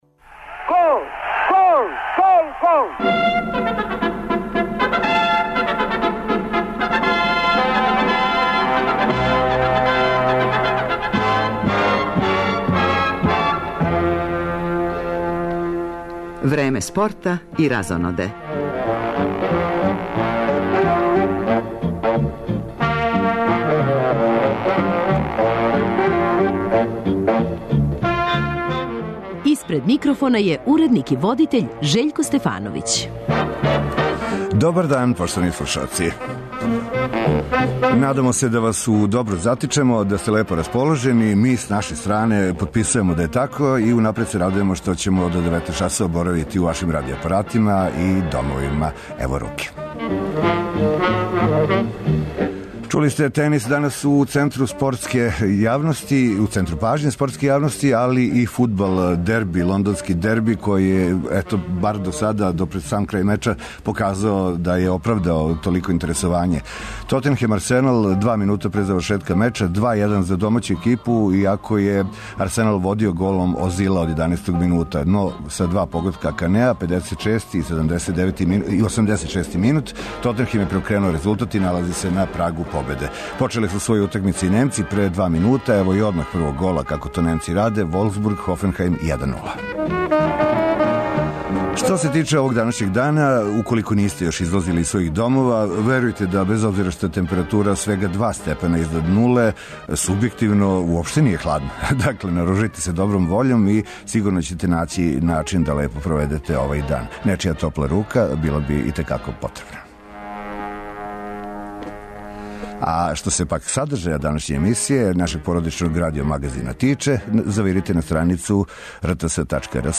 Као и свака емисија из овог циклуса, и данашње Време спорта и разоноде саткано је од низа прилога, разговора и репортажа везаних за спортска и дешавања у јавном и културном животу.